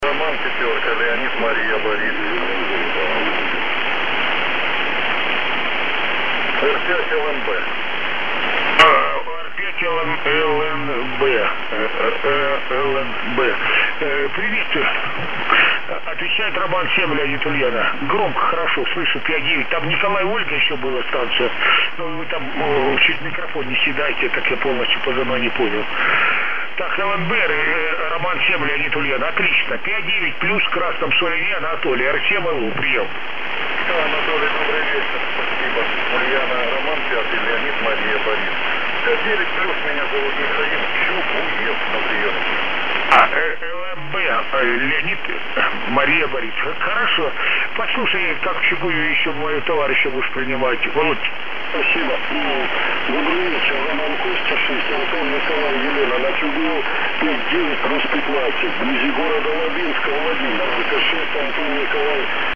С АРУ нужно позаниматься, слышно как бы поздно срабатывает.
80meters3.mp3